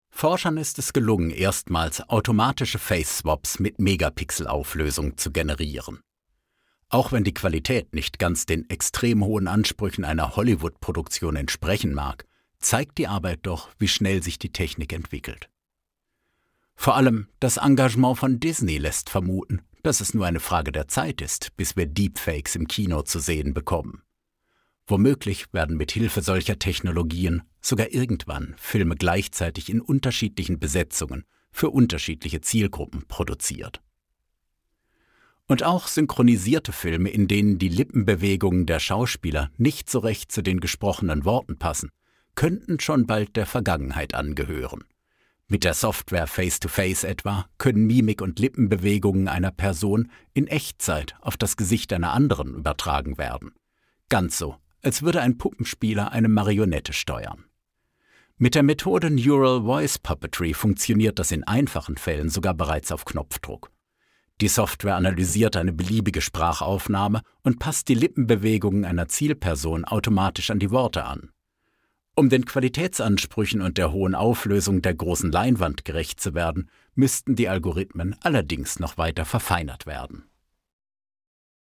Male
Approachable, Authoritative, Character, Confident, Conversational, Cool, Corporate, Engaging, Friendly, Natural, Reassuring, Smooth, Versatile, Warm
Standard German, English with German/European accent
Microphone: Blue Bluebird SL